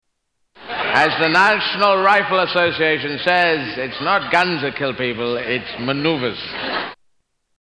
Category: Comedians   Right: Personal
Tags: Comedians Eddie Izzard Eddie Izzard Soundboard Eddie Izzard Clips Stand-up Comedian